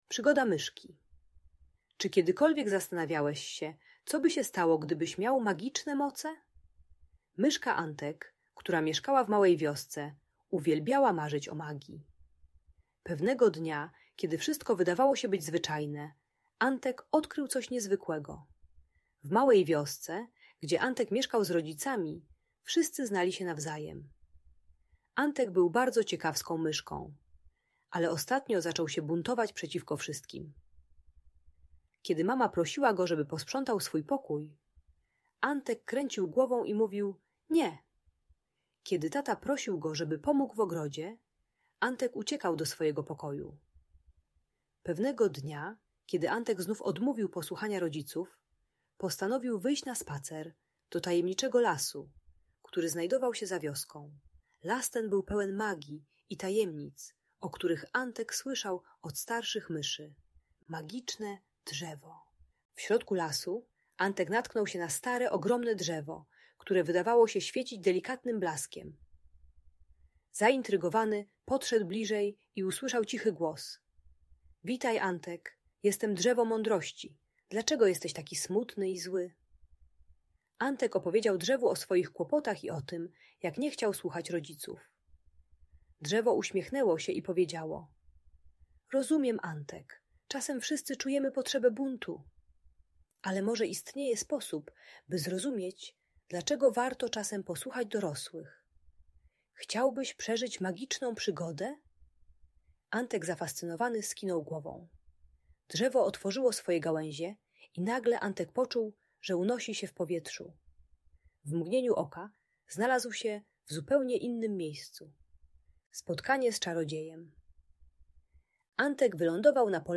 Przygoda Myszki Antka - Bunt i wybuchy złości | Audiobajka